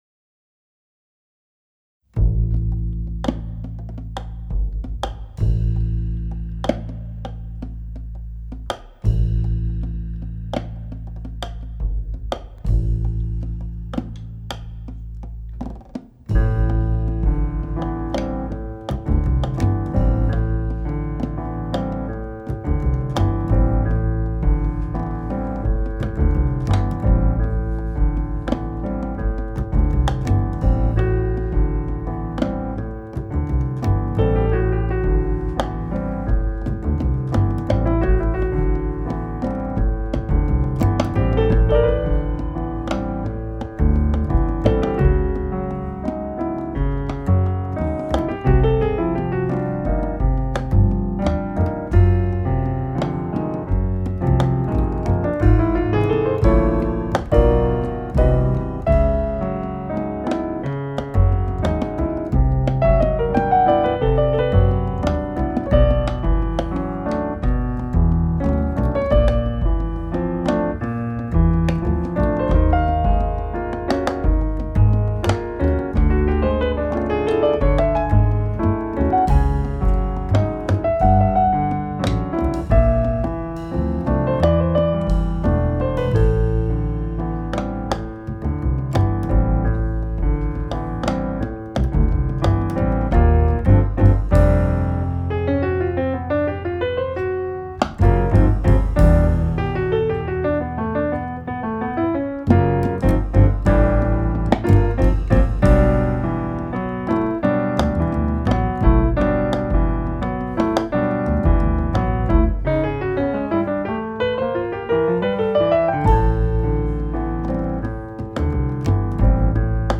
bass
drums